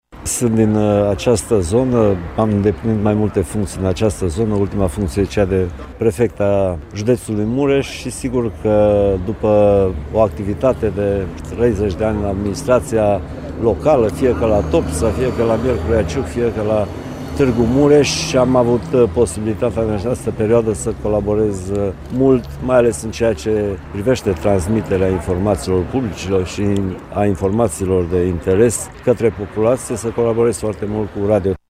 Îl ascultăm într-unul dintre mesajele transmise în anul 2018: